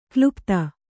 ♪ klupta